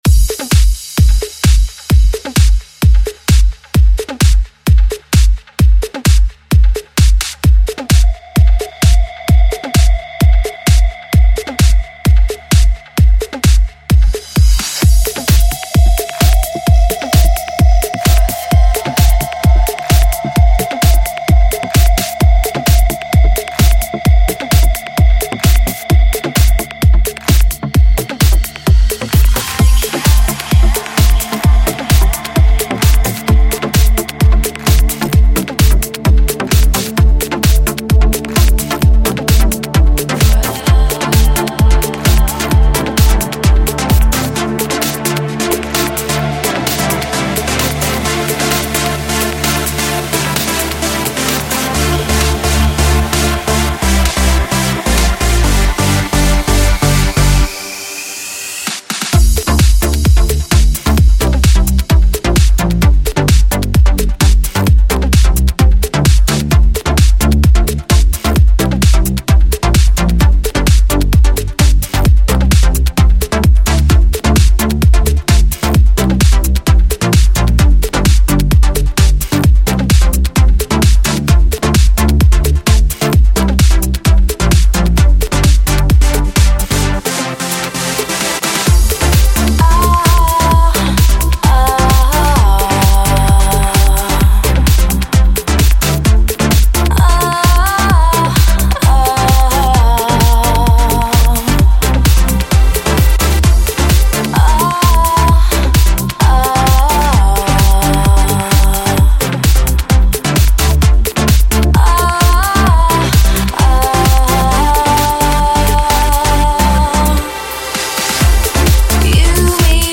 Жанр: Trance
клубная транс вокал музыка